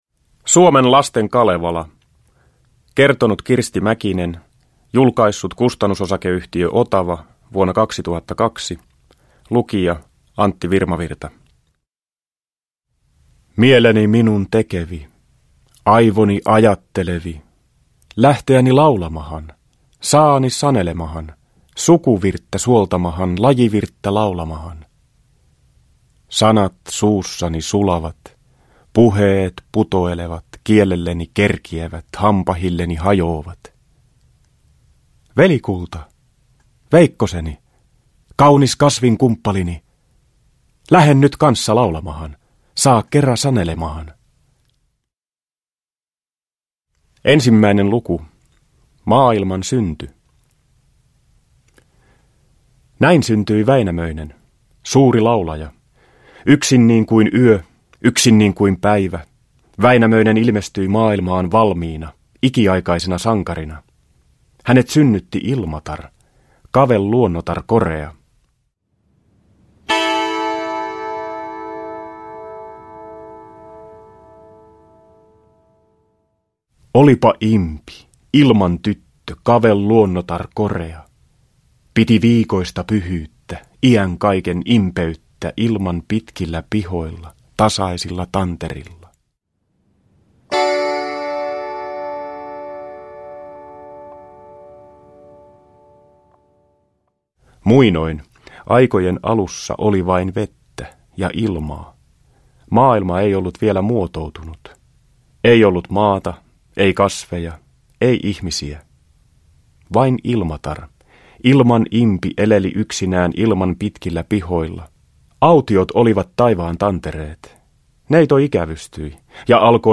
Suomen lasten Kalevala – Ljudbok – Laddas ner
Uppläsare: Antti Virmavirta